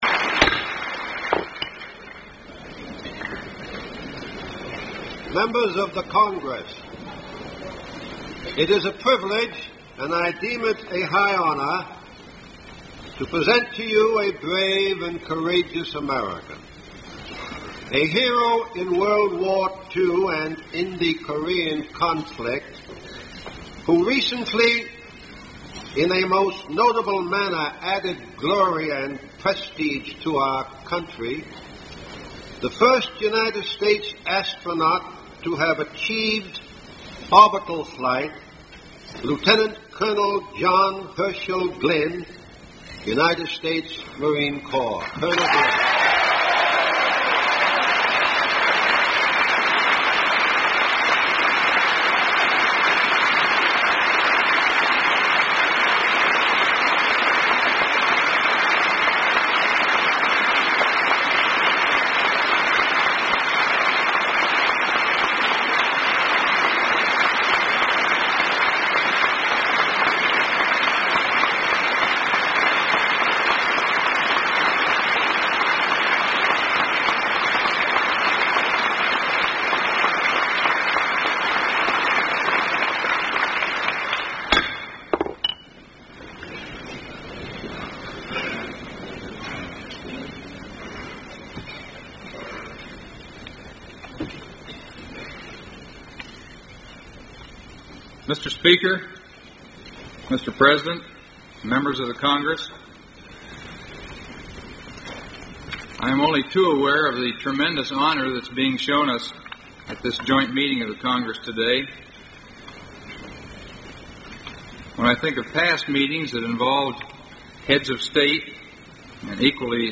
Address before a joint session of the U.S. Congress
Broadcast on Voice of America, February 26, 1962.